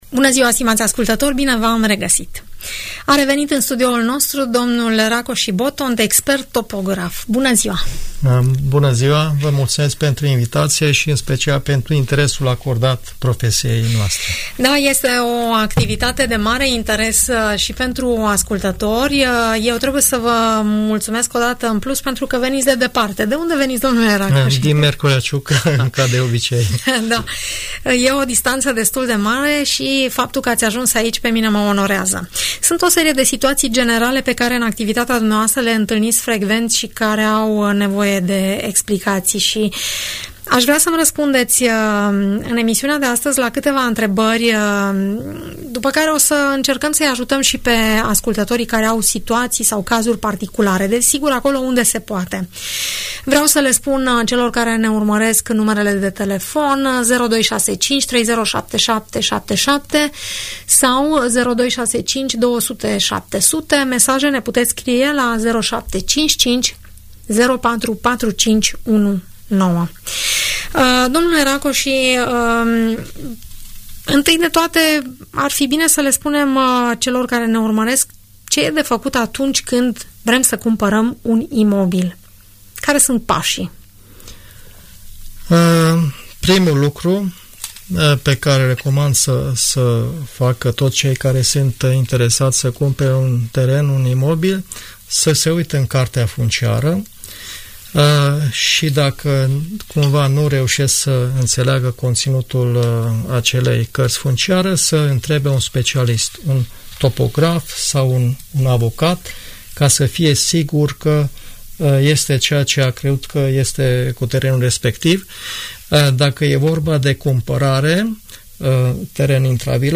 expert în domeniul topografiei răspunde în emsiunea „Părerea ta” de la Radio Tg. Mureș, la cele mai frecvente întrebări referitoare la întocmirea documentației cadastrale, legea posesiei, sau diferitelor situații particulare ridicate de către ascultătorii emisiunii „Părerea ta”.